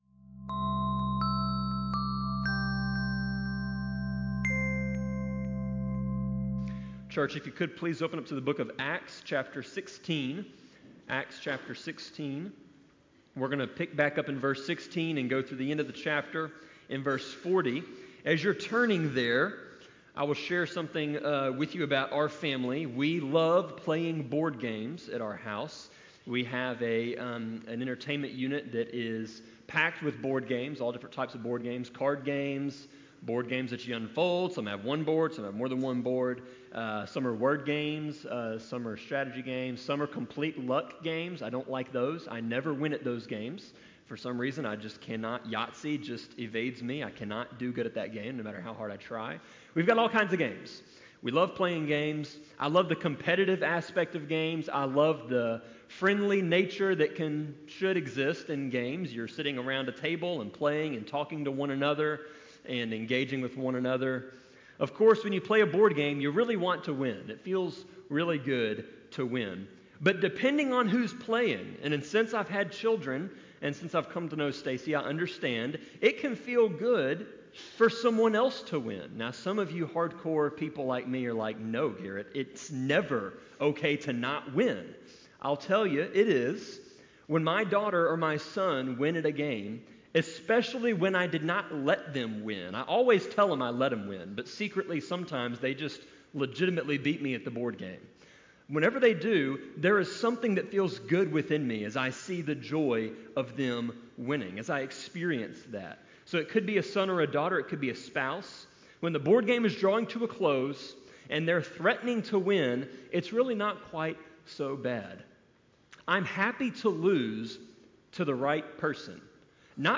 Sermon-24.7.21-CD.mp3